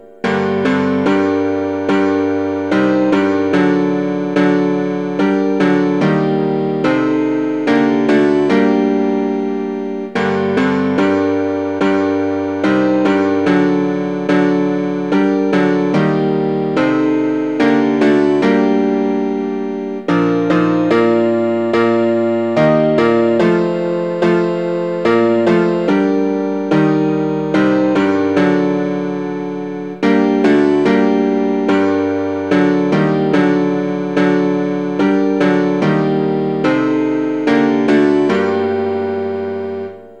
Midi file is modified to piano only.